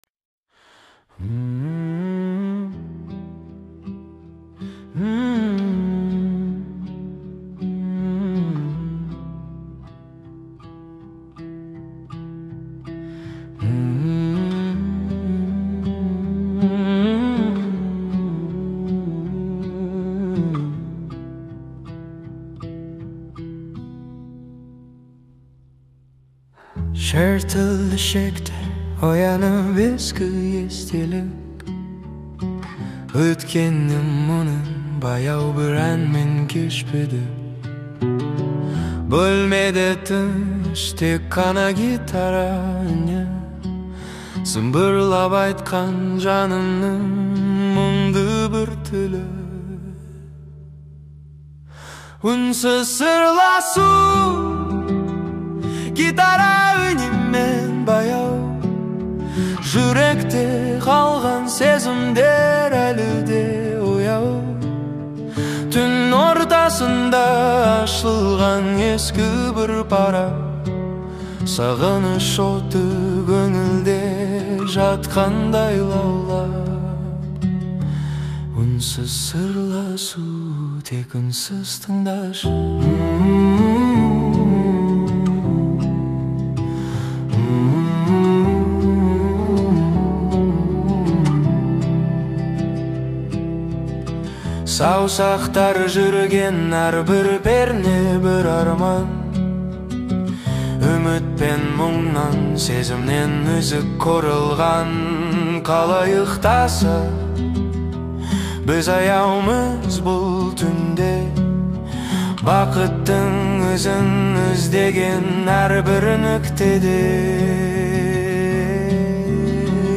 Жаныңызға ем болатын гитара үні